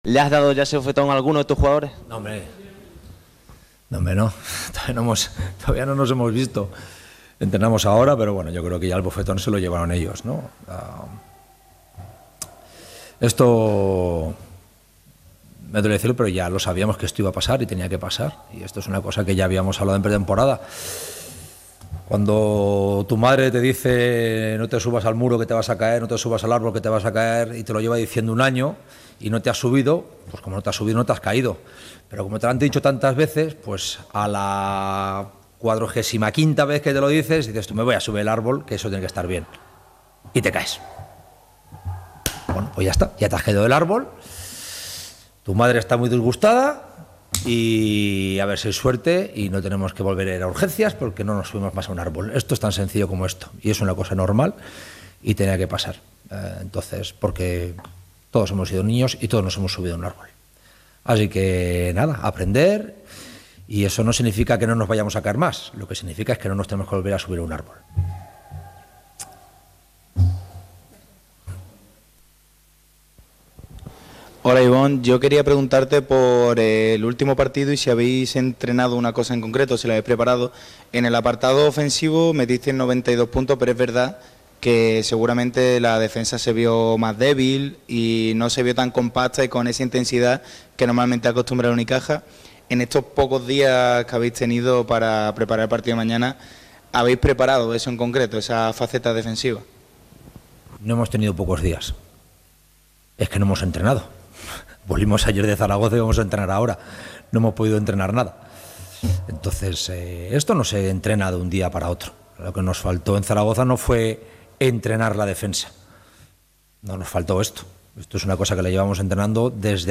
Ibon Navarro, entrenador del Unicaja, compareció en sala de prensa en el Carpena en la previa de la tercera jornada de Liga Endesa ante Valencia Basket. El técnico vasco reconoció que lo importante de la derrota no era el hecho de caer derrotado, sino el cómo, por la pérdida de identidad.